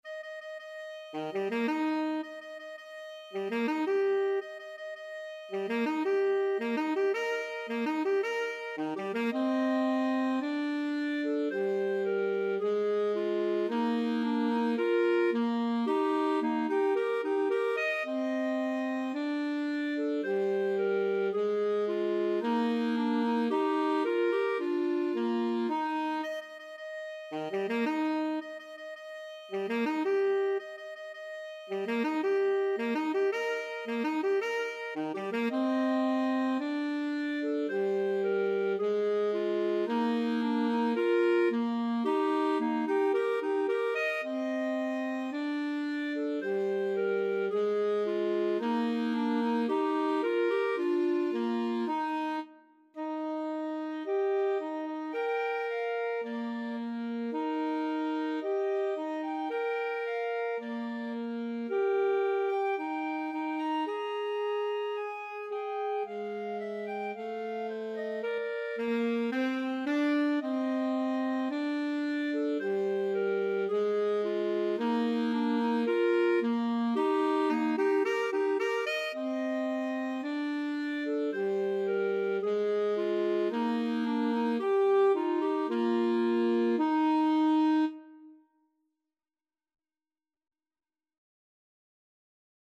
ClarinetAlto Saxophone
Andante maestoso =c.110 =110